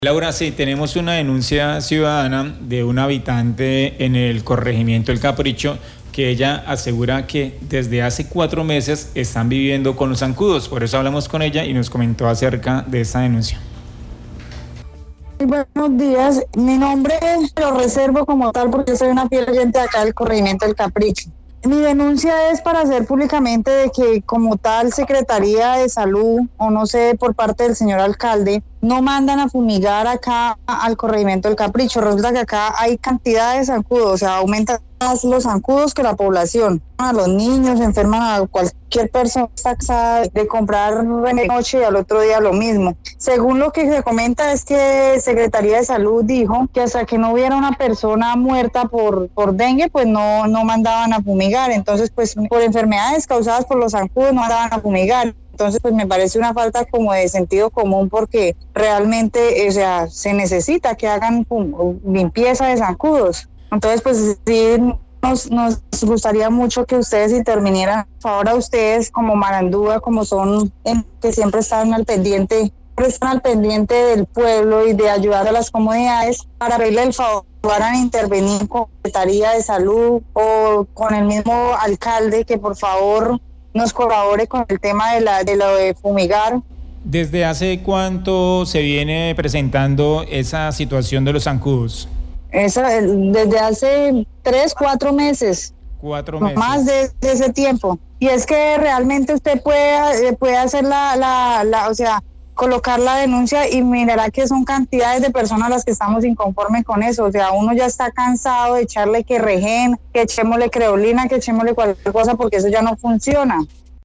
En Marandua Noticias, una ciudadana dio a conocer esta situación y agregó que desde hace cuatro meses hay presencia de los zancudos, que vienen perjudicando principalmente a menores de edad.